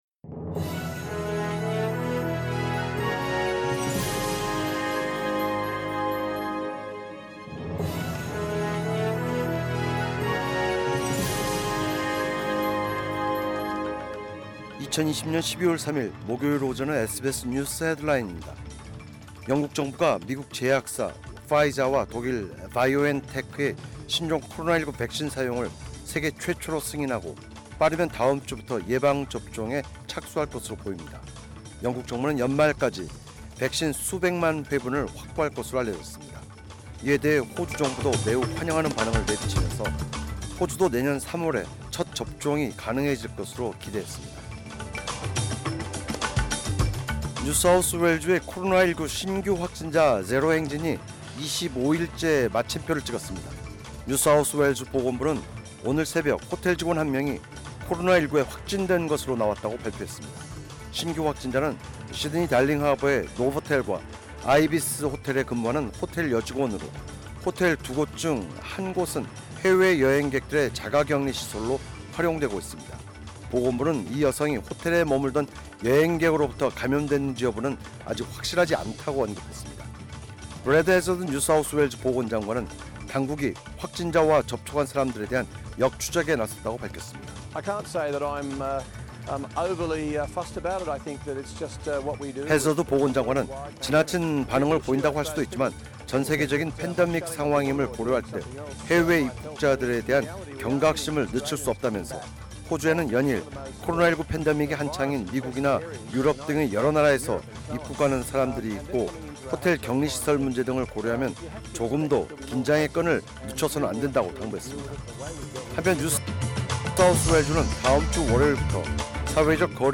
2020년 12월 3일 목요일 오전의 SBS 뉴스 헤드라인입니다.